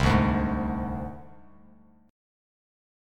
Csus2#5 chord